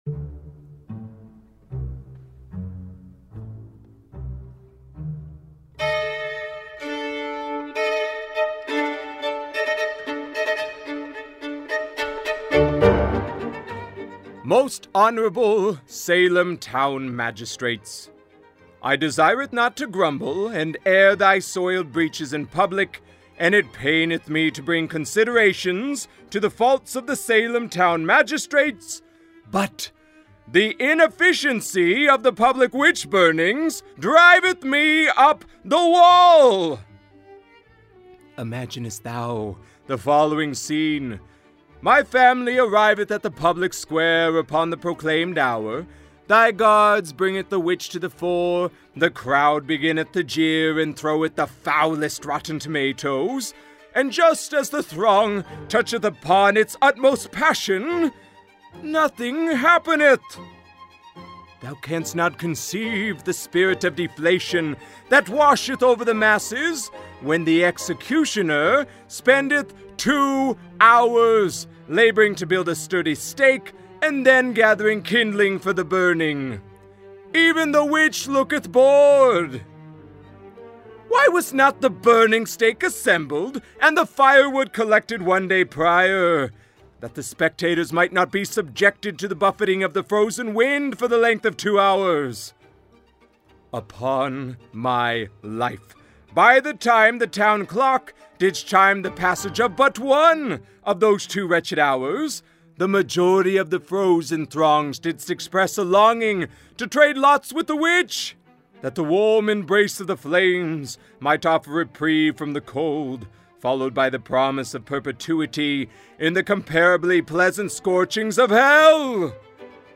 at DB Studios in Shanghai, China
The musical selection, Danse Macabre by Camille Saint-Saens, can be downloaded for free at the Internet Archive)